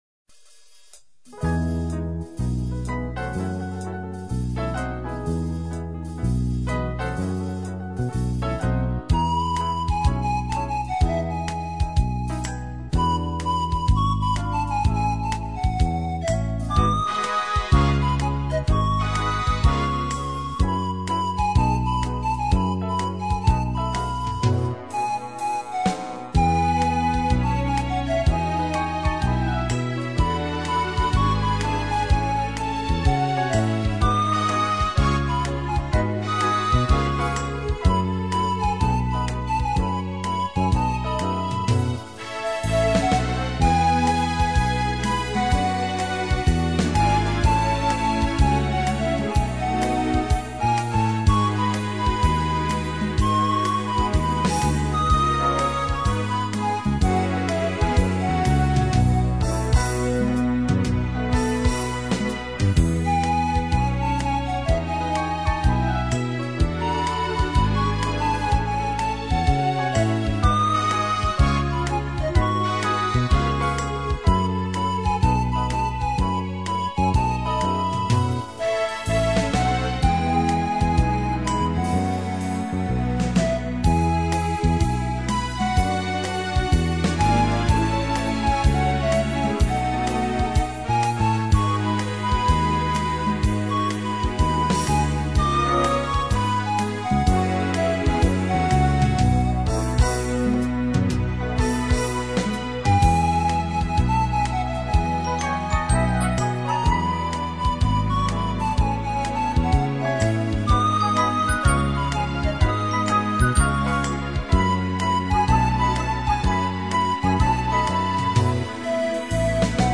所属分类：轻 音 乐